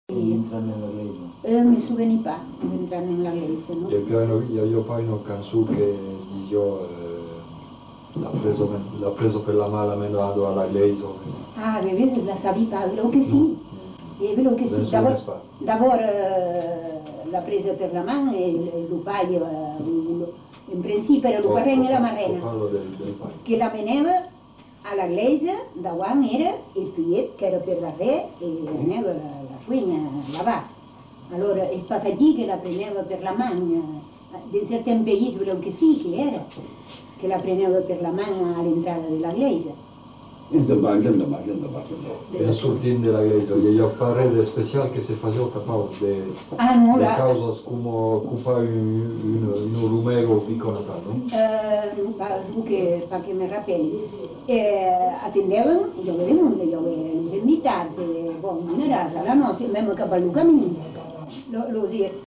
Aire culturelle : Marmandais gascon
Lieu : Tonneins
Genre : témoignage thématique